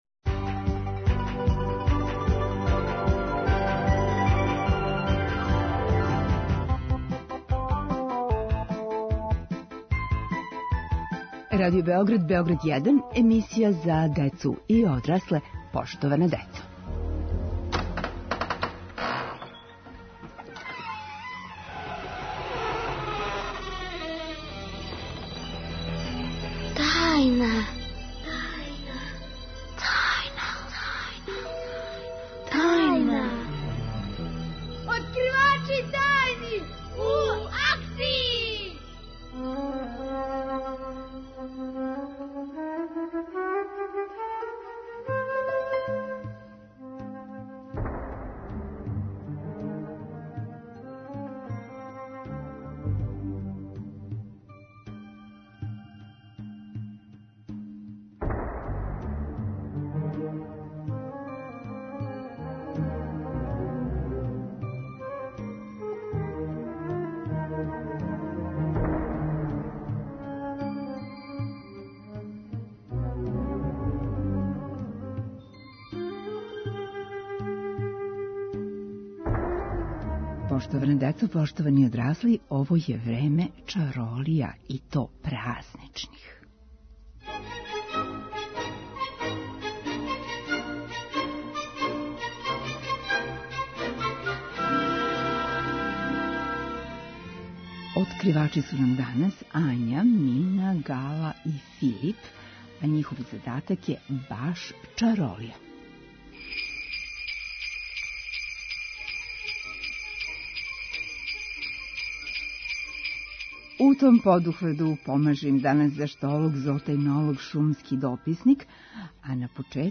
Откривачи су деца, а у овим подухвату помажу им: Заштолог, Зоотајнолог и шумски дописник.